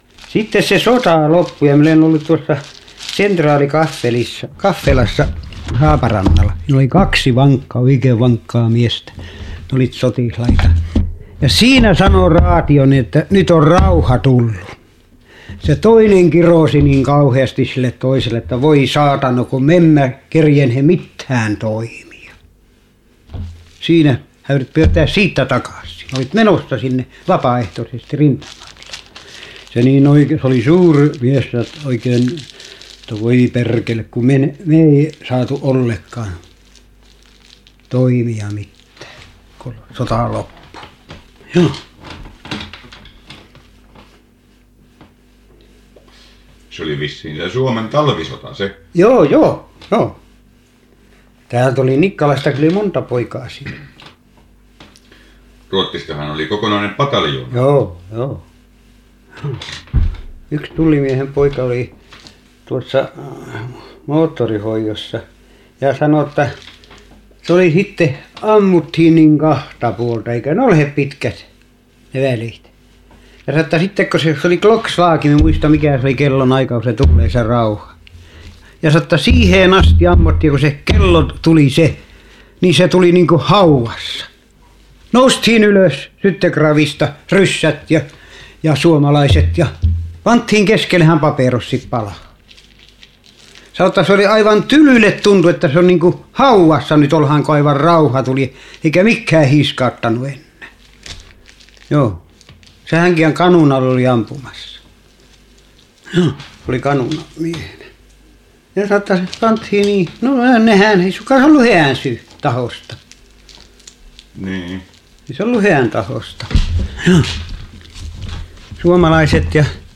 Inspelningarna innehåller huvudsakligen fritt tal av så kallade informanter, och berättelser om ämnen som de känner till, minnen och erfarenheter.
Inspelningarna gjordes från och med slutet av 1950-talet under intervjuresor med arkivets personal och insamlare som fått finansiering.